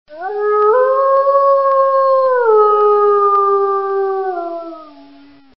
Звук волчьего воя для видеомонтажа